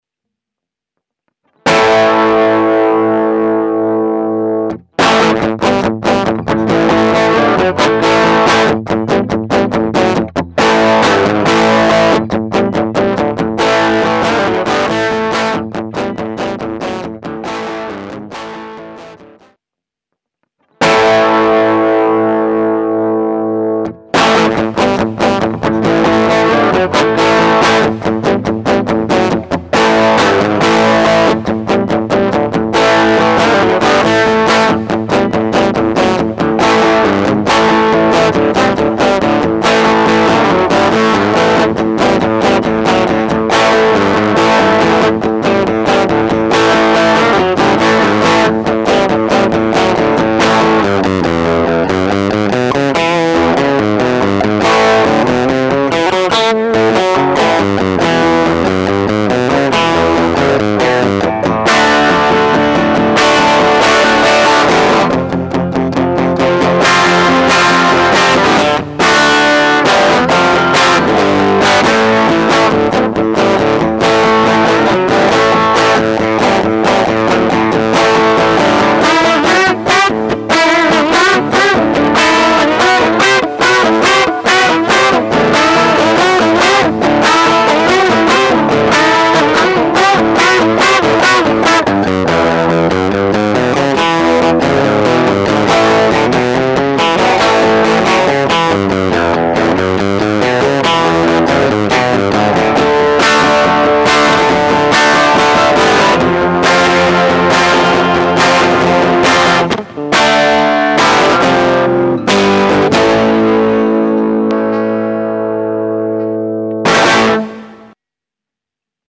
The following audio clips used an indentical basic setup to show the capabilities and quality that can be obtained by using the AMP+.
The guitar was plugged straight into the Super 57 or M59 AMP+ and then routed into a Focusrite Saffire 6 USB Interface.
Some of the clips have a dry section 1st and then with added post production reverb to show what a typical studio track might sound like.